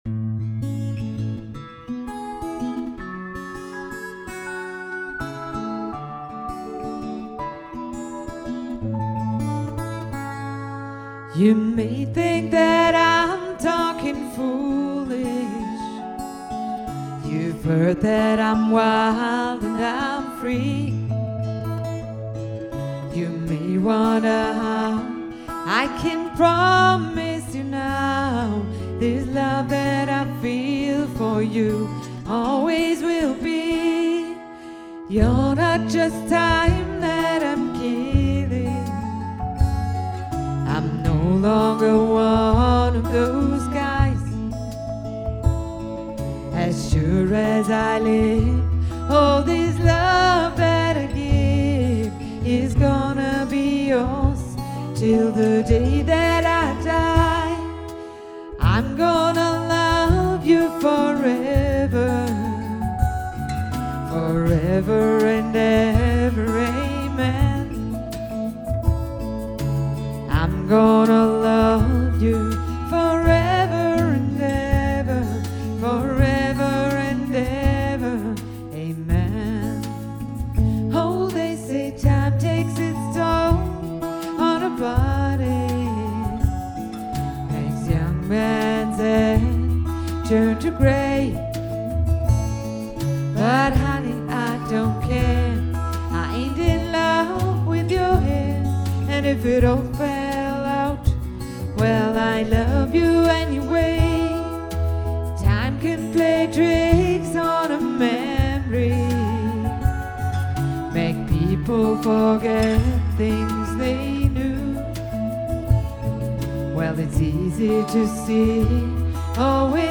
And I liked the addition of the second voice.